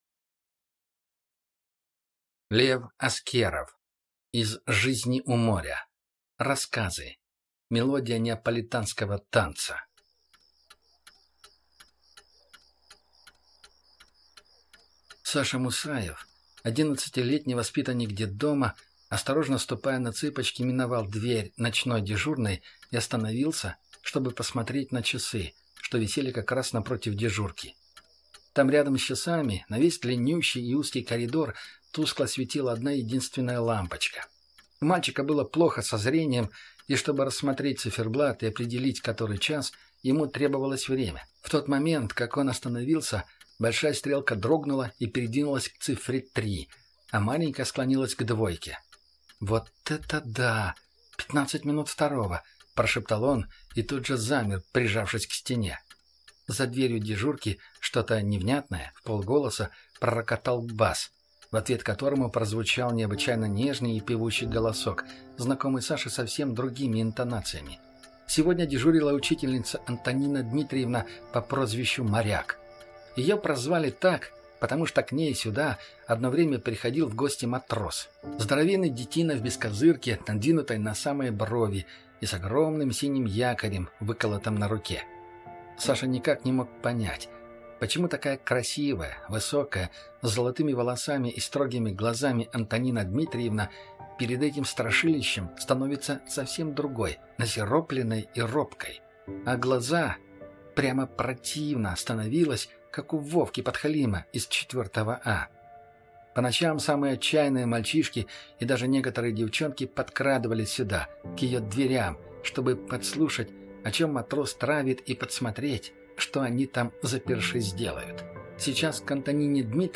Аудиокнига Мелодия неаполитанского танца | Библиотека аудиокниг
Прослушать и бесплатно скачать фрагмент аудиокниги